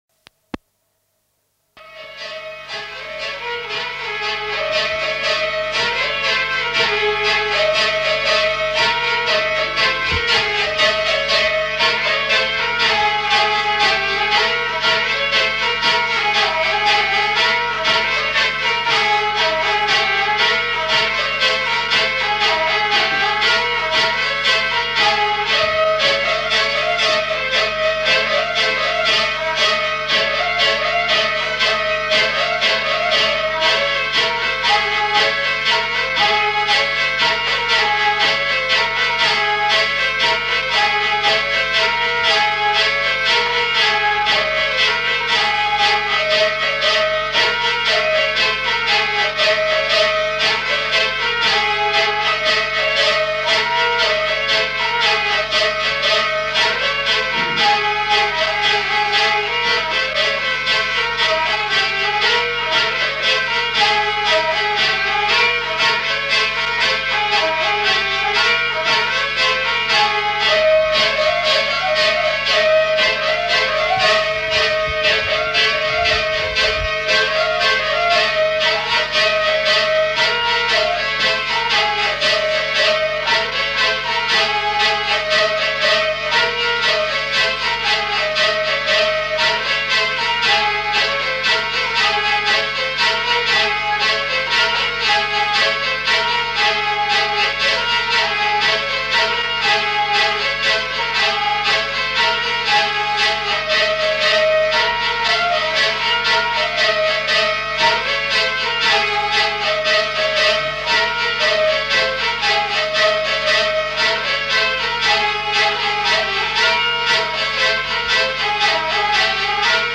Aire culturelle : Gabardan
Lieu : Mauléon-d'Armagnac
Genre : morceau instrumental
Instrument de musique : vielle à roue
Danse : courante